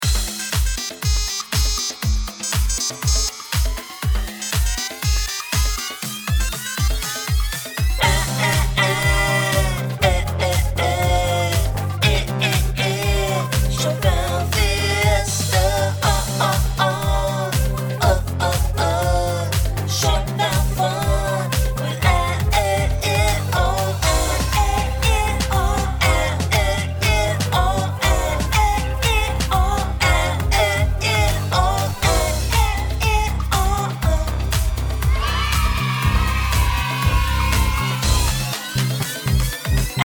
festive
Listen to a sample of this song.